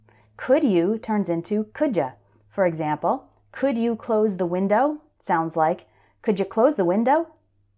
One reason for the difficulty is that when native English speakers are talking fast, we often change how we pronounce the words at the beginning of questions.
COULD YOU >> COULDJA